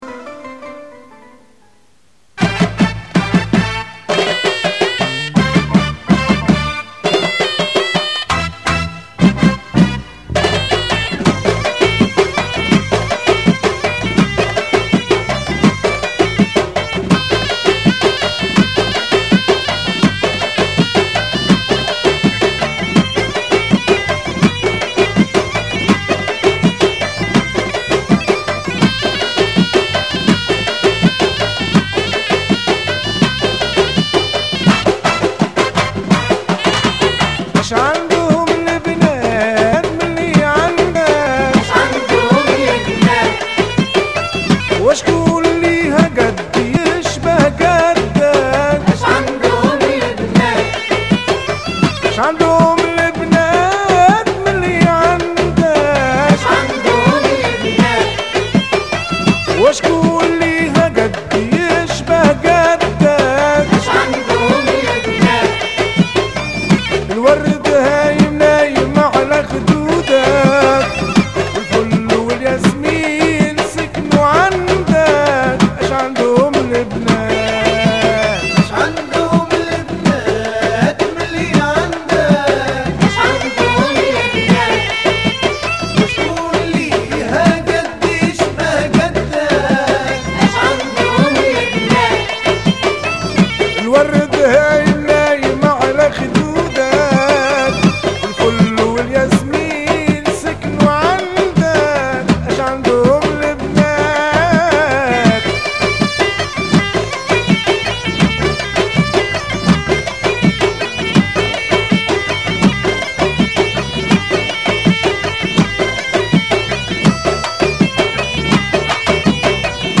Bienvenue au site des amateurs de Mezoued Tunisien
la chanson